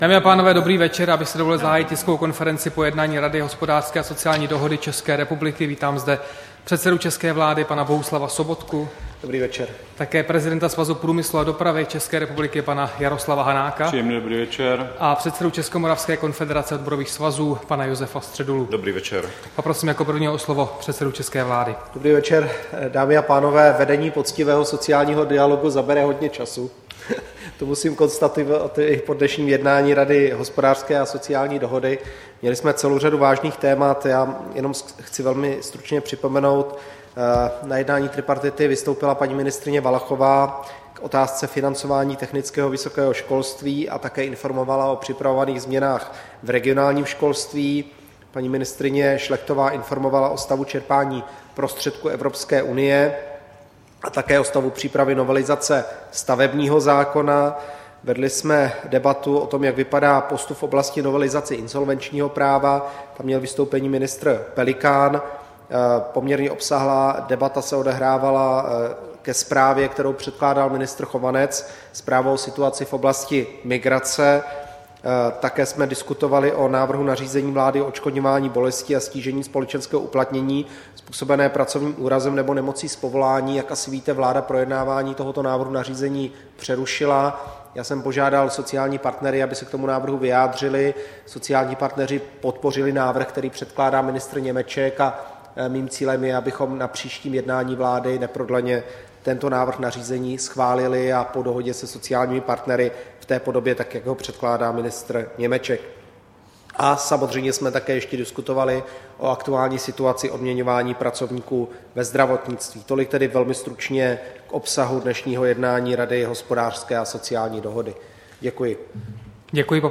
Tisková konference po jednání tripartity, 5. října 2015